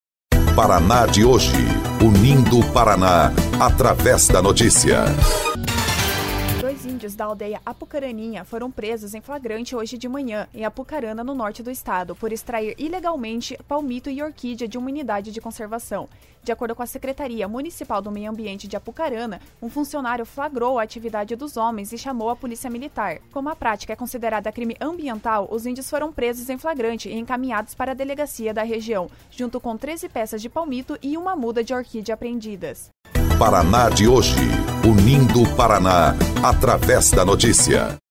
14.12 – BOLETIM – Dois índios foram presos por praticar crime ambiental em Apucarana